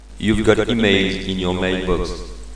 Newmail.mp3